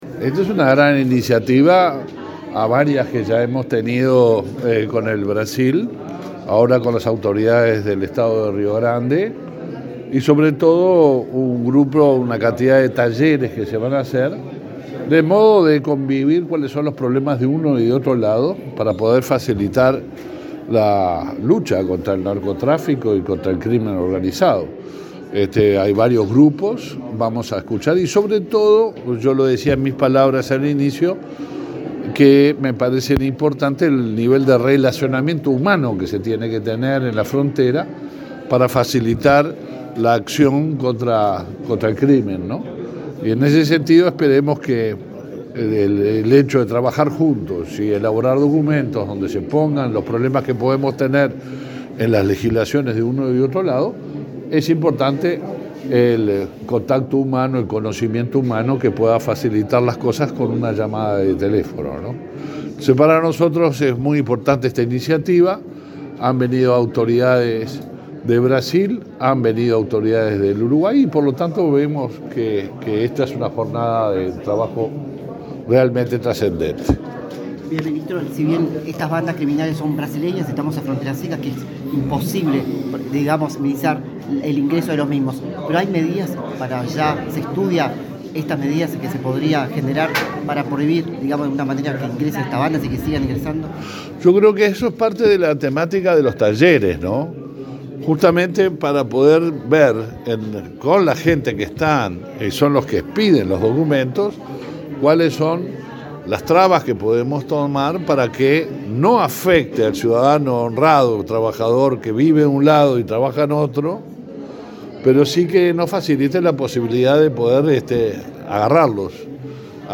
Declaraciones del ministro del Interior, Luis Alberto Heber
Declaraciones del ministro del Interior, Luis Alberto Heber 30/08/2022 Compartir Facebook X Copiar enlace WhatsApp LinkedIn El ministro del Interior, Luis Alberto Heber; el canciller, Francisco Bustillo, y el ministro de Defensa Nacional, Javier García, participaron en Rivera en un encuentro binacional entre Uruguay y Brasil sobre seguridad pública. Luego, Heber dialogó con la prensa.